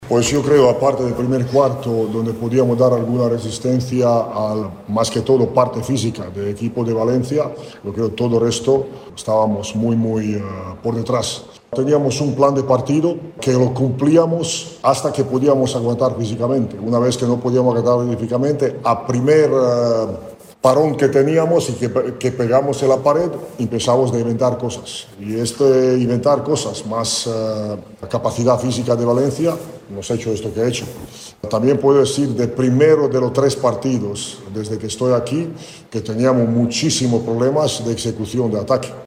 Escoltem a l’entrenador, Zan Tabak.